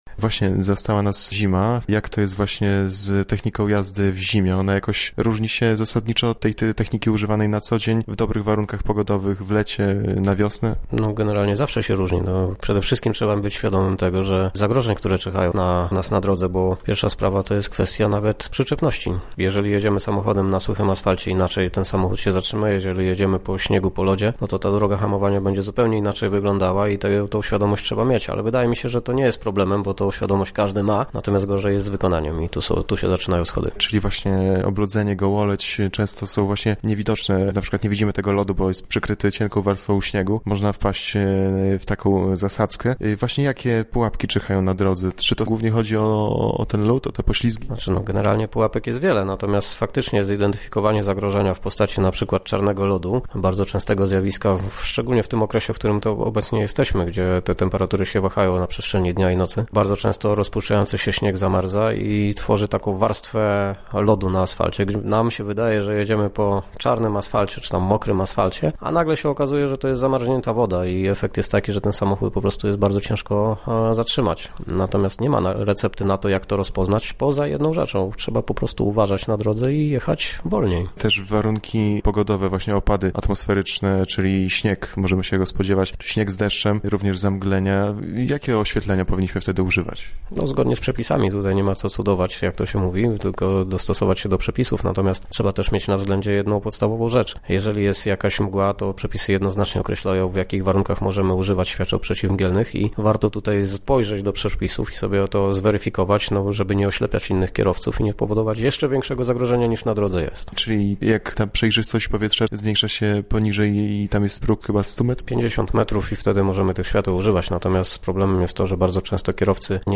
instruktor.mp3